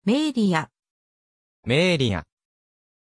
Prononciation de Maélia
pronunciation-maélia-ja.mp3